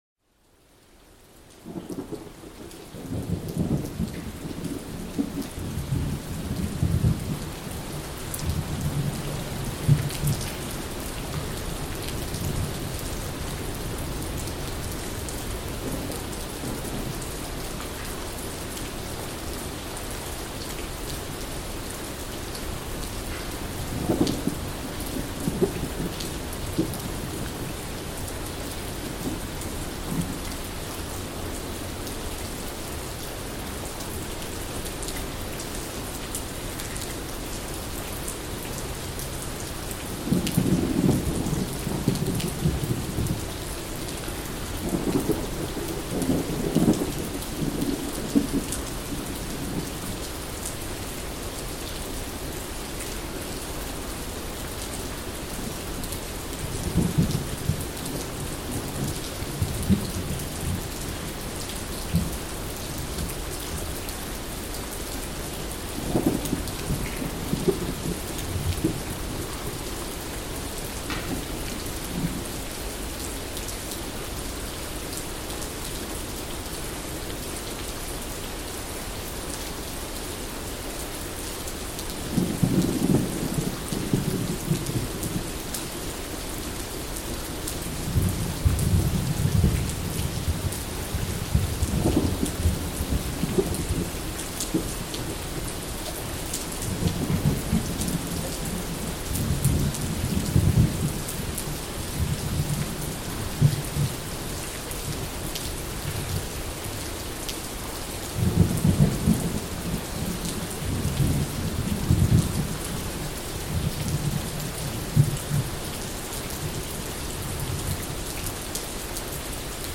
Gewitter am Höhleneingang – Regen & Donner für Schlaf & Entspannung
Ein einzelner Regentropfen durchbricht die absolute Stille und beginnt seine epische Reise vom dunklen Gewitterhimmel hinab auf das warme Dach Ihres sicheren Zeltes im tiefen Wald.